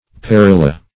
Perilla \Pe*ril"la\, prop. n. [Etymol. uncertain.]